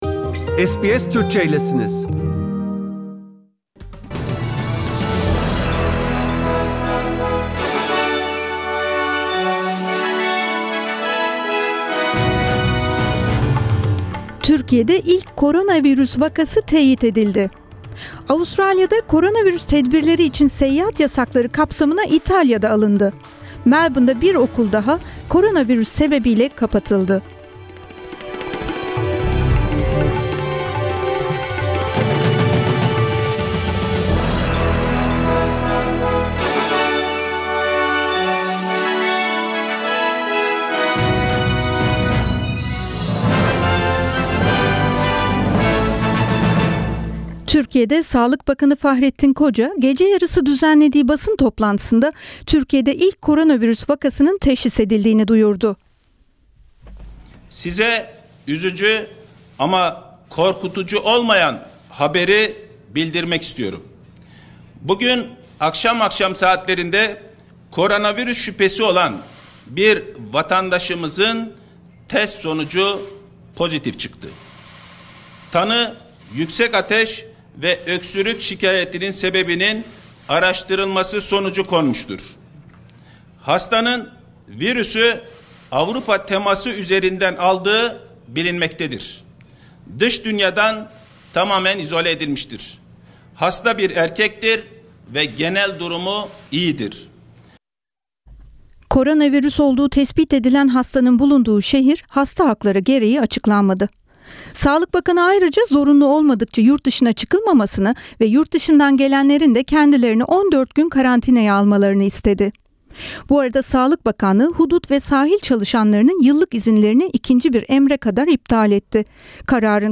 SBS Türkçe Haber Bülteni - Mart 11, 2020 Çarşamba